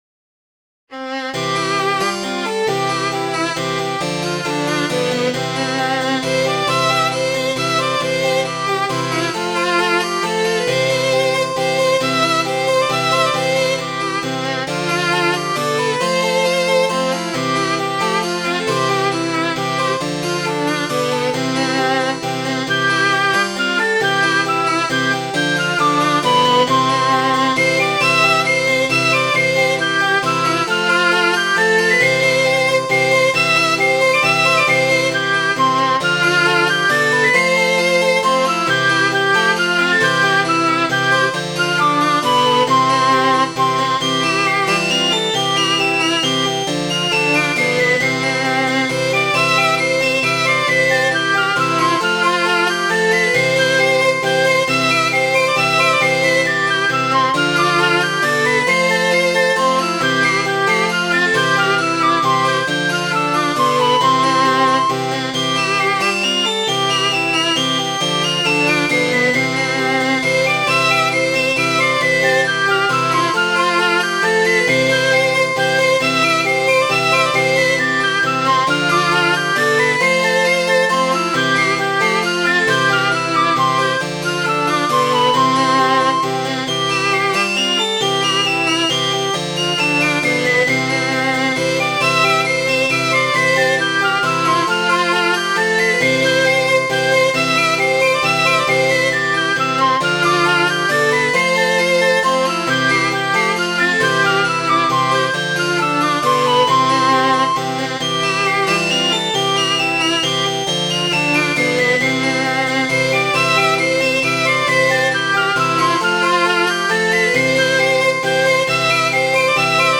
Midi File, Lyrics and Information to The Ploughboy (Lark in the Morn)